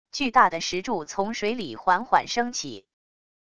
巨大的石柱从水里缓缓升起wav音频